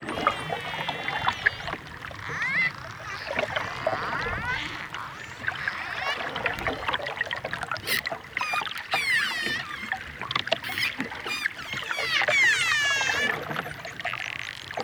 Dolphins.wav